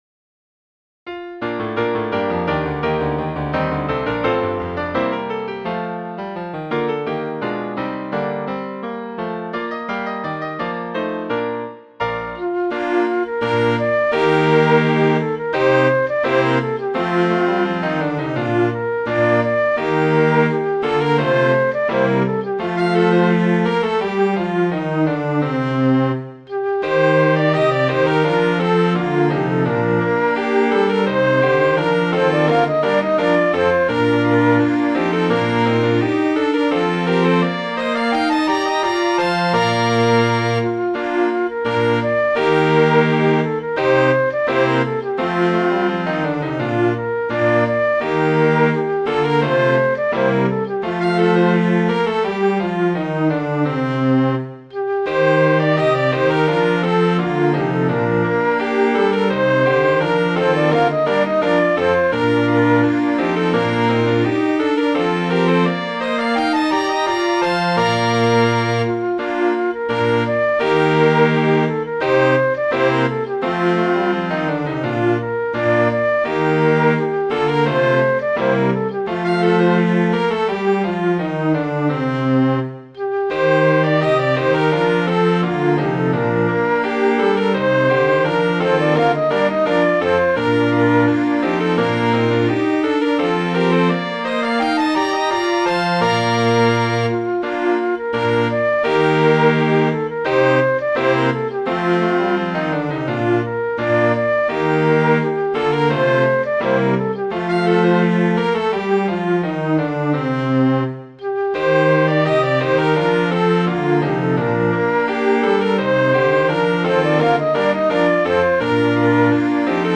Midi File, Lyrics and Information to The Bay of Biscay